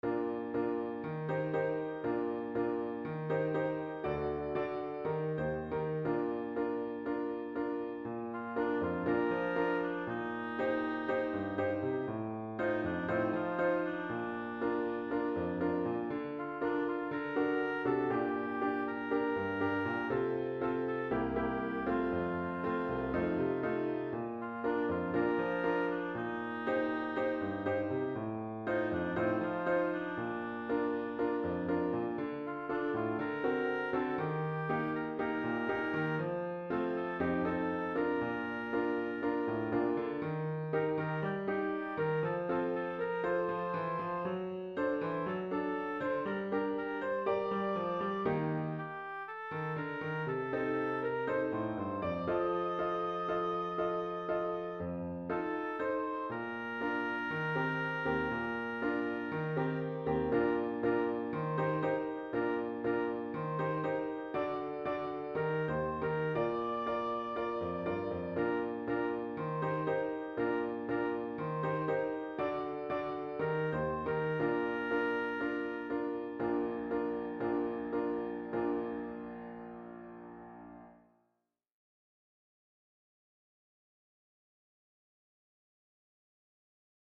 英語の入った少しポップな内容の詩でした。